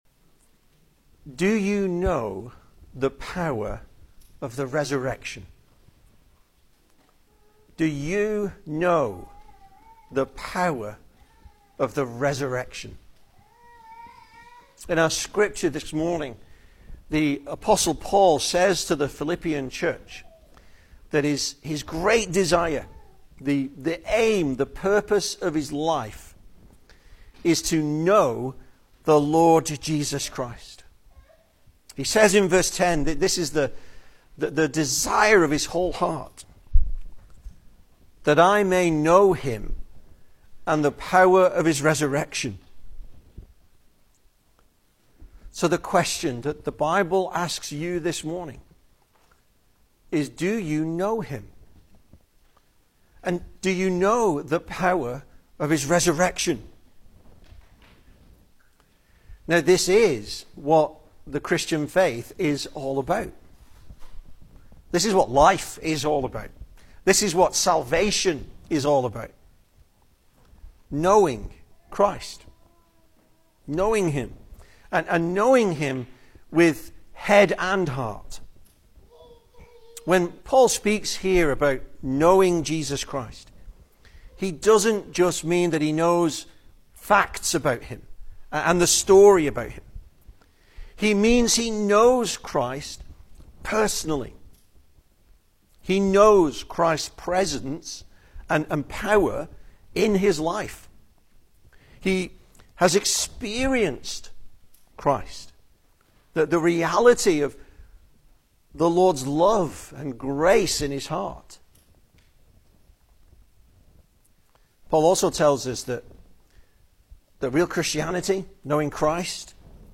Service Type: Sunday Morning
Easter Sermons